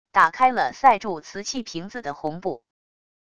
打开了塞住瓷器瓶子的红布wav音频